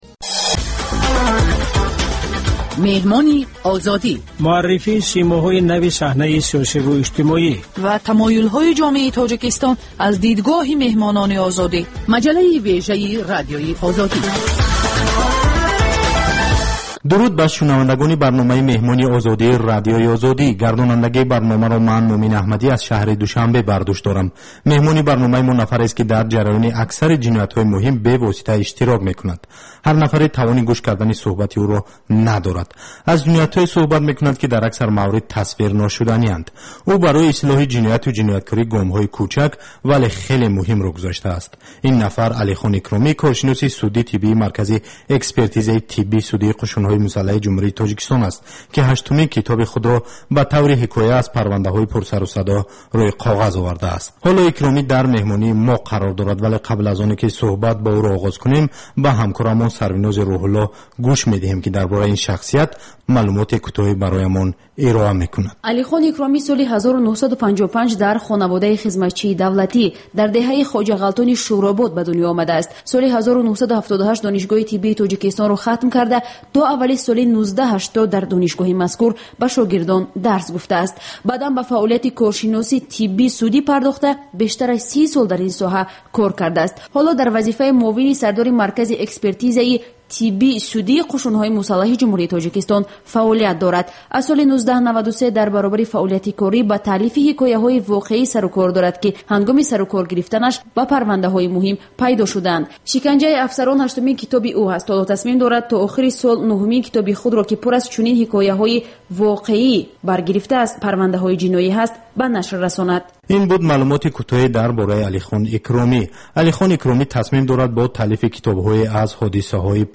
Сӯҳбати ошкоро бо чеҳраҳои саршинос ва мӯътабари Тоҷикистон бо пурсишҳои сангин ва бидуни марз.